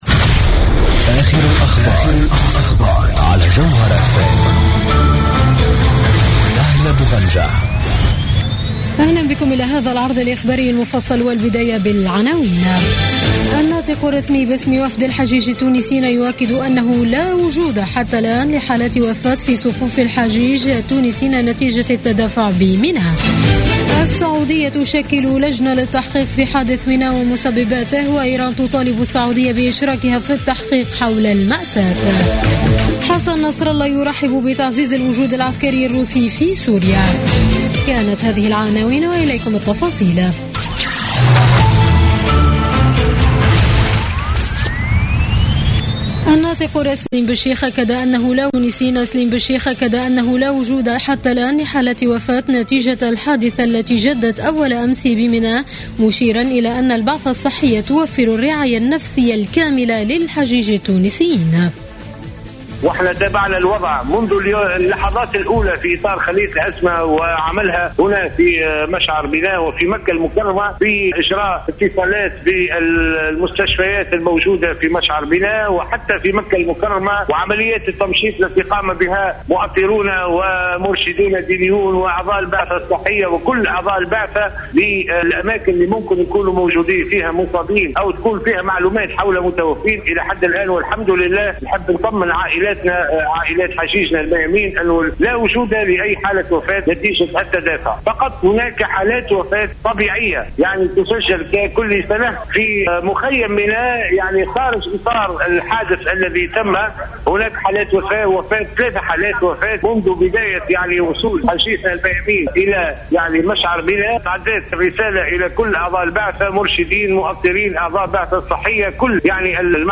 نشرة أخبار منتصف الليل ليوم السبت 26 سبتمبر 2015